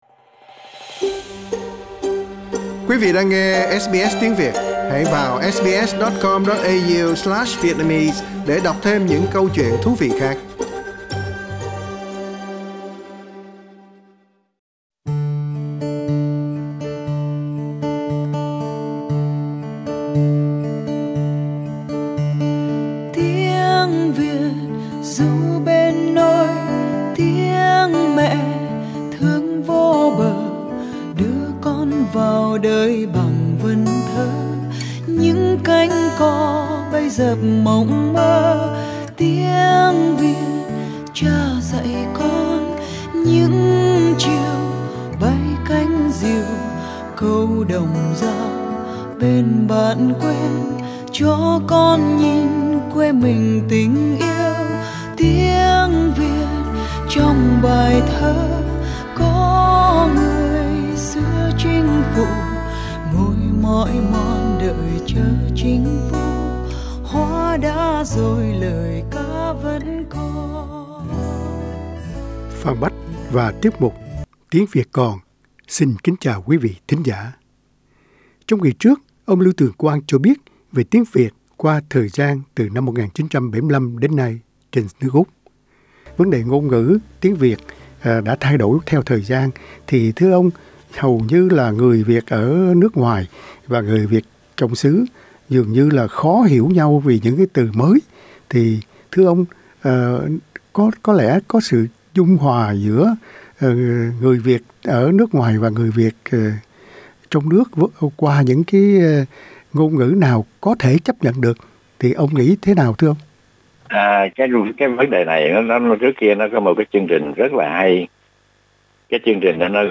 trả lời phỏng vấn